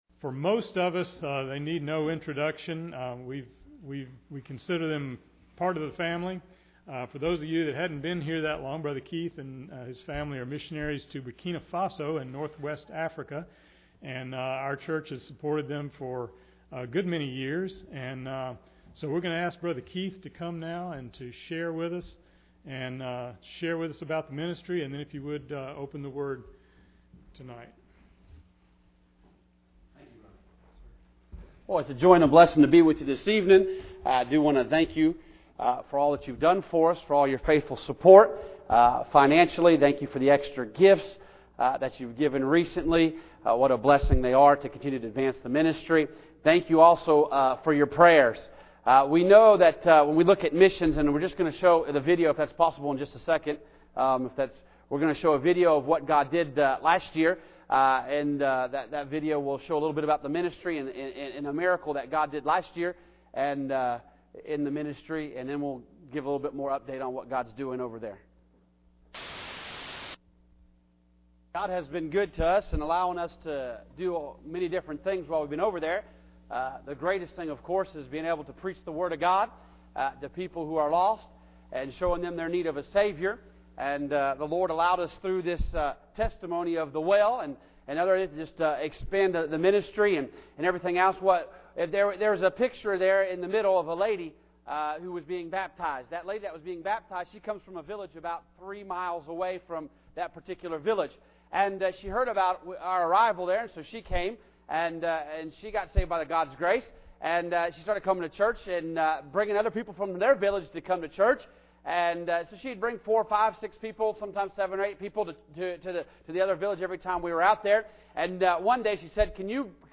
Nehemiah 6:1-4 Service Type: Wednesday Evening Bible Text